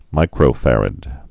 (mīkrō-fărəd, -ăd)